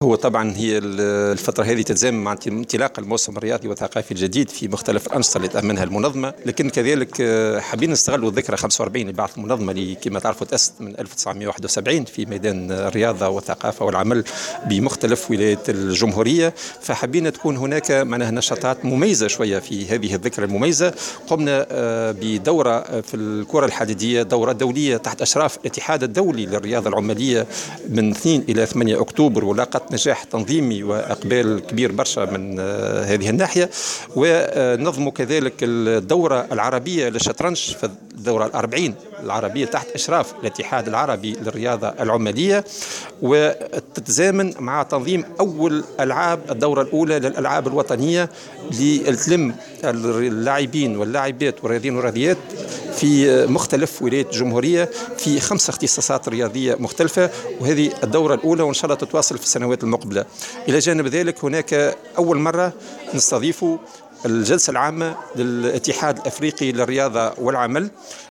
عقدت المنظمة الوطنية للثقافة والرياضة والعمل ندوة صحفية اليوم بإحدى نزل العاصمة للحديث حول نشاط المنظمة خلال الفترة السابقة و توضيح دورها في دعم الأنشطة الثقافية و الرياضية بالبلاد إضافة إلى عرض برنامج إحتفال المنظمة بالذكرى 45 لتأسيسها.